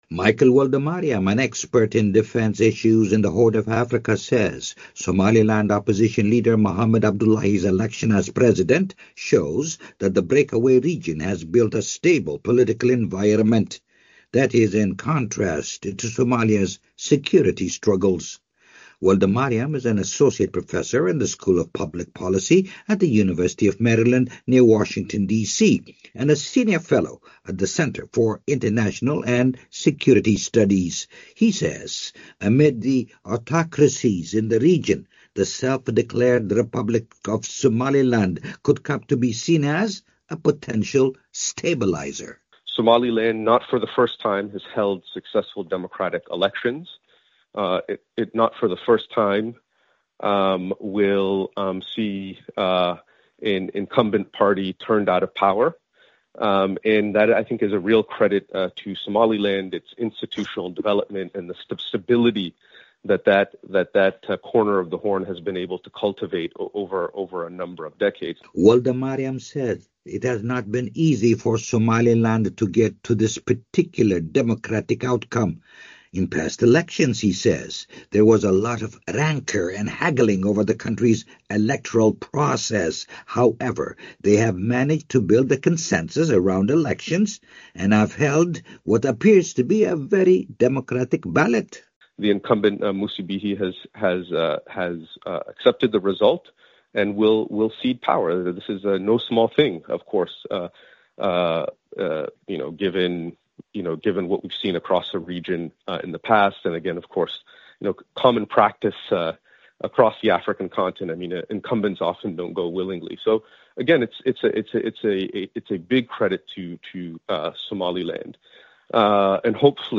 Here’s more of their conversation.